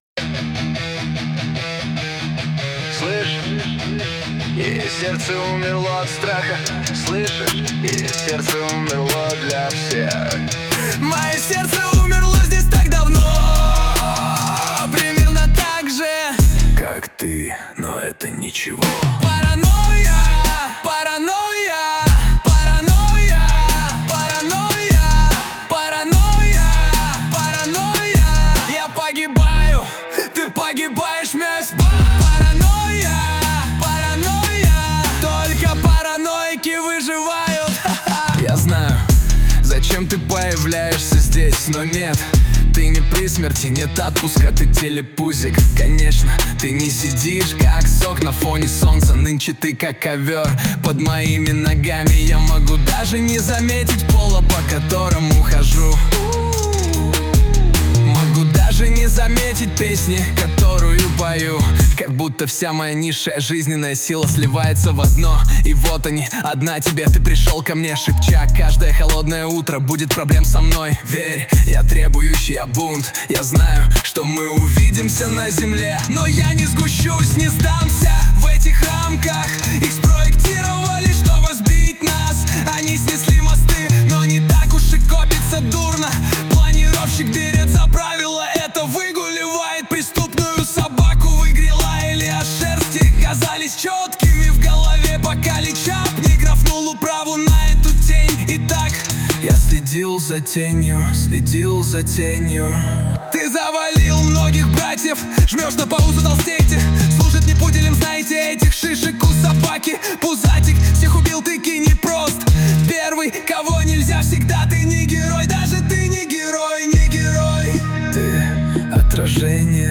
RUS, Rap | 17.03.2025 16:30